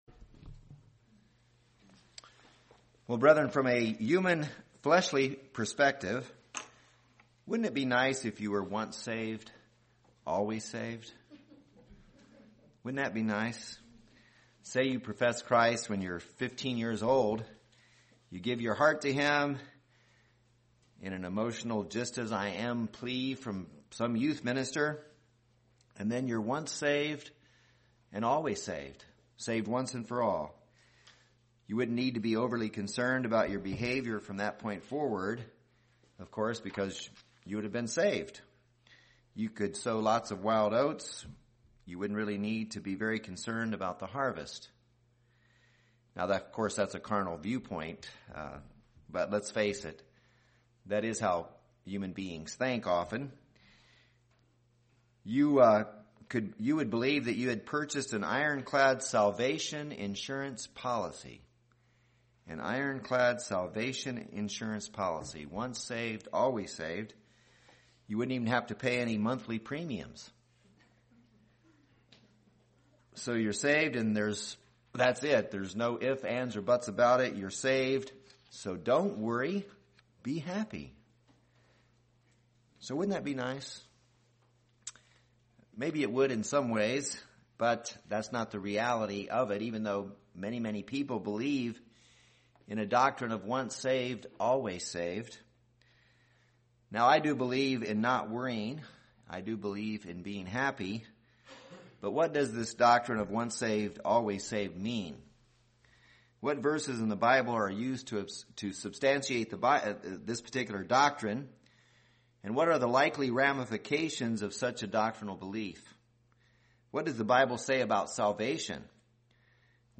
This sermon exposes biblical errors in the popular nominal Christian doctrine of "Once Saved Always Saved".